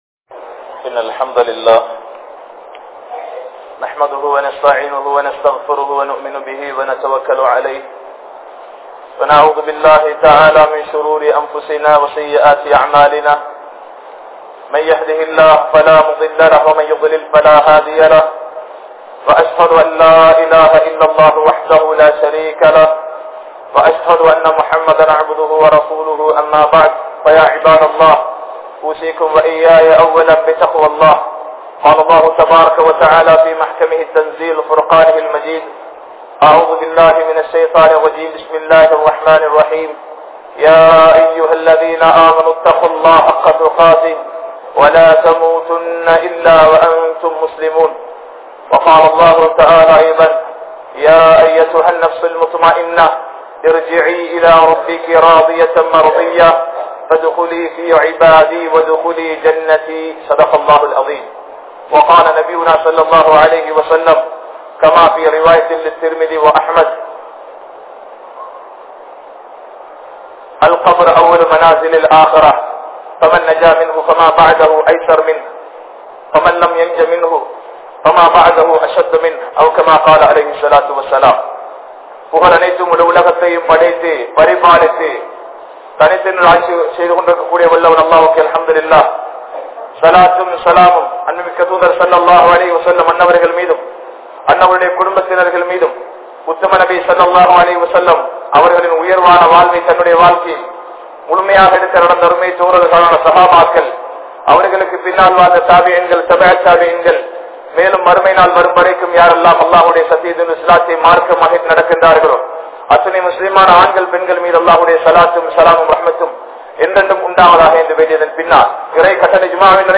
Mannaraiyai Maranthu Vidaatheerhal (மண்ணறையை மறந்து விடாதீர்கள்) | Audio Bayans | All Ceylon Muslim Youth Community | Addalaichenai